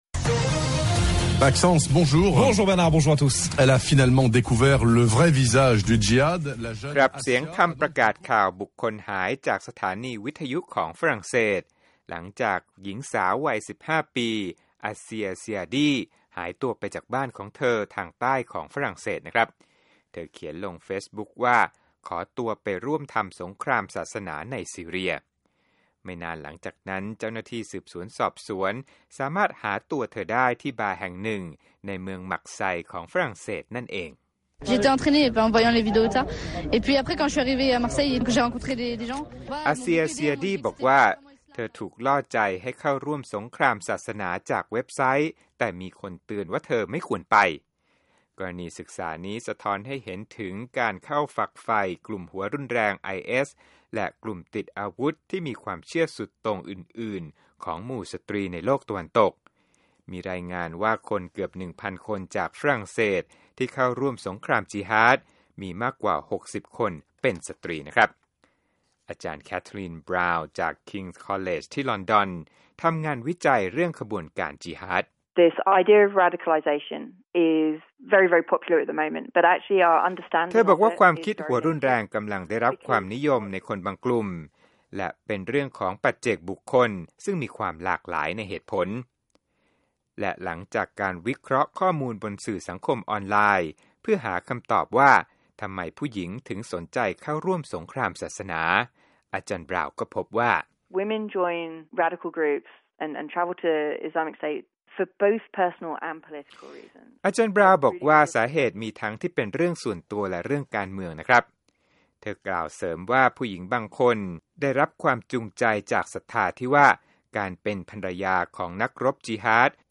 โปรดติดตามรายละเอียดจากคลิปเรื่องนี้ในรายการข่าวสดสายตรงจากวีโอเอ